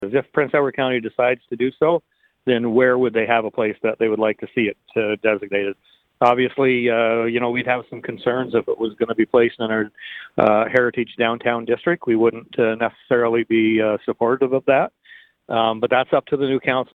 Mayor Robert Quaiff says they want as much information as possible.